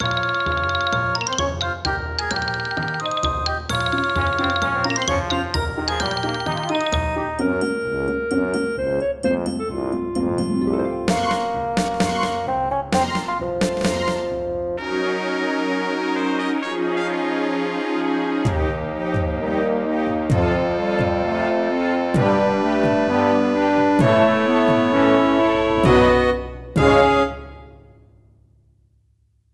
I'm running a single midi file through various synths.
Edirol Hyper Canvas
midiTest01EdirolHyperCanvas01.mp3